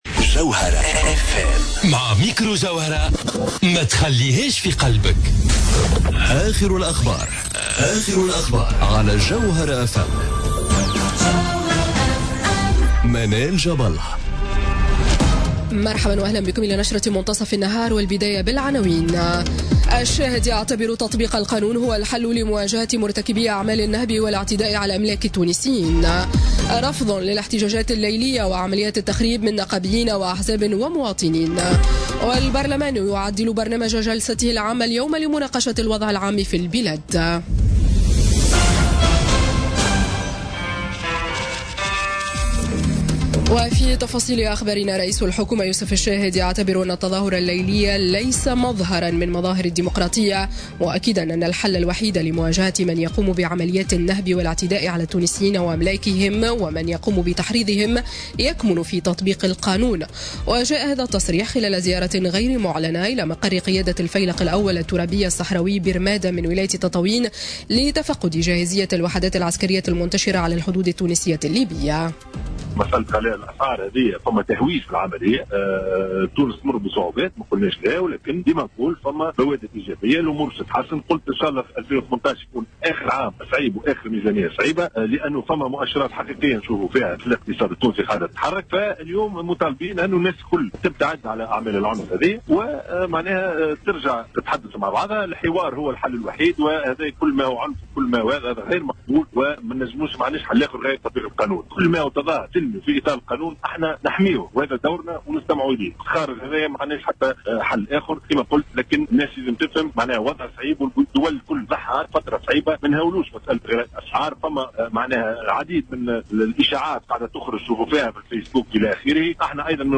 نشرة أخبار منتصف النهار ليوم الثلاثاء 9 جانفي 2018